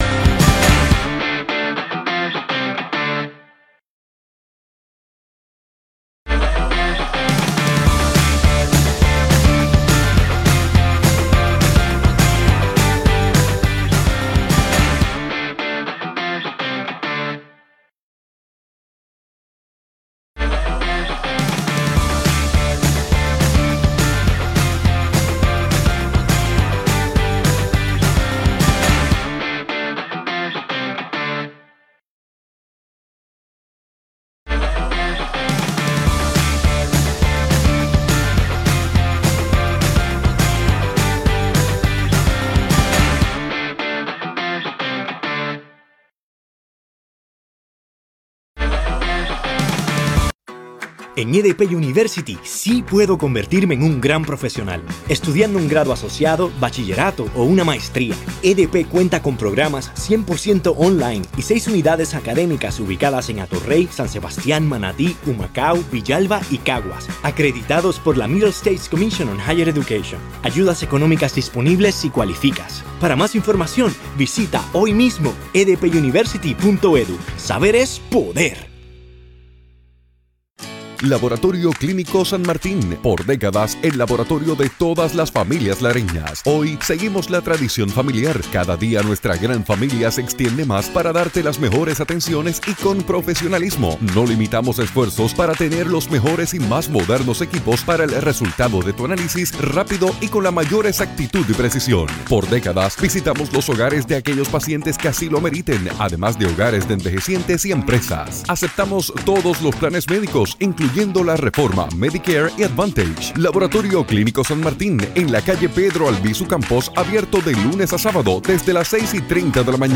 llegan desde Plaza Las Américas en el junte Radial para hablarnos sobre todo lo relacionado a aviación comercial.